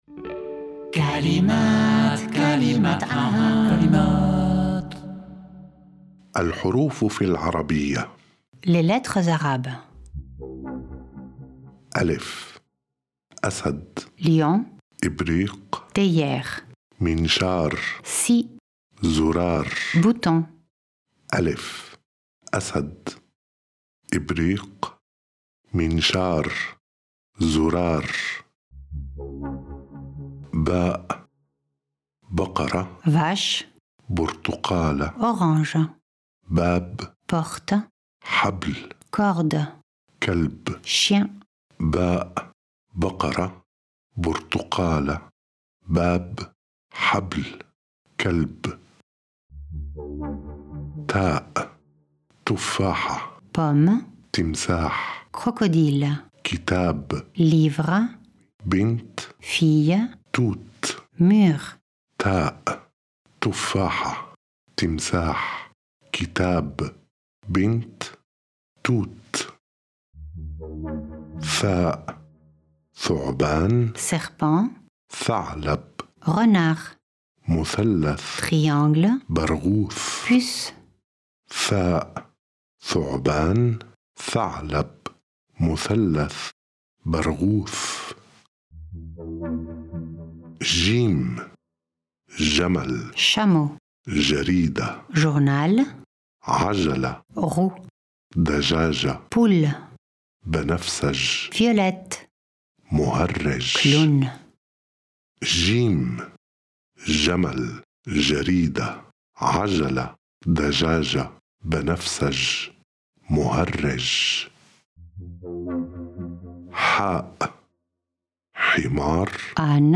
création musicale
lecture en français
lecture en arabe